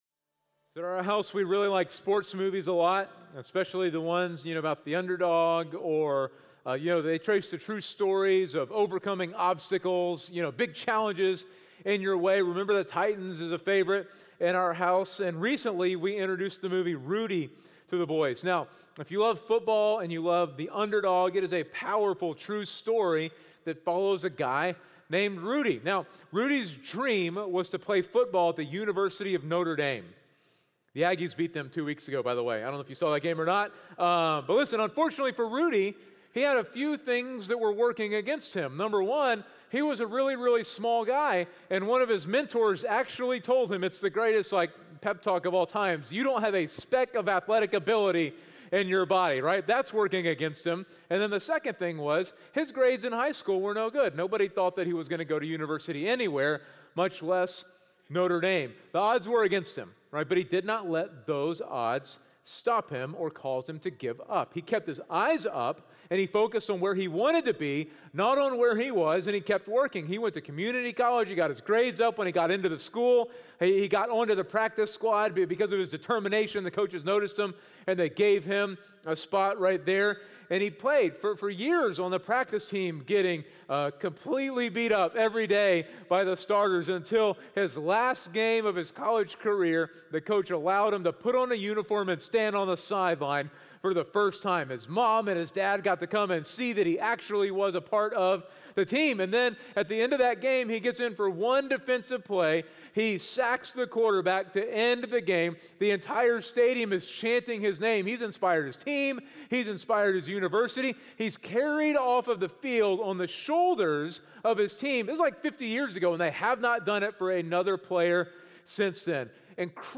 Message: “A Living Faith”